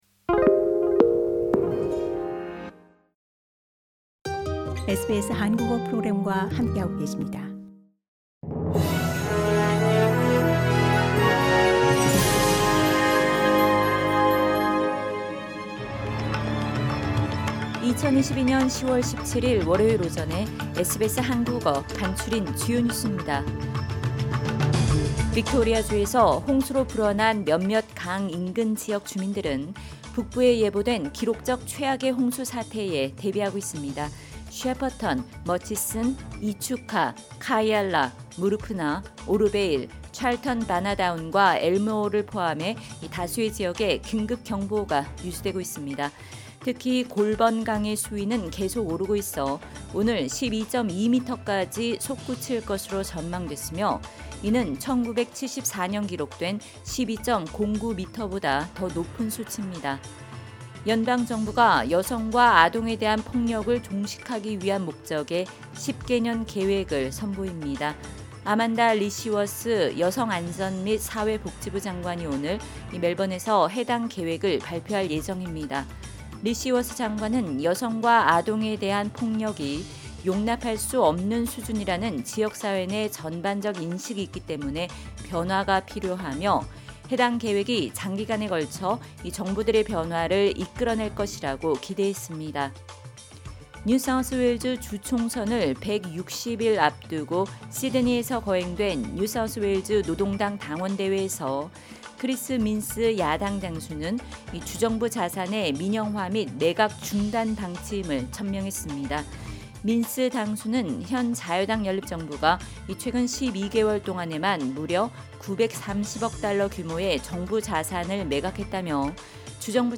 SBS 한국어 아침 뉴스: 2022년 10월 17일 월요일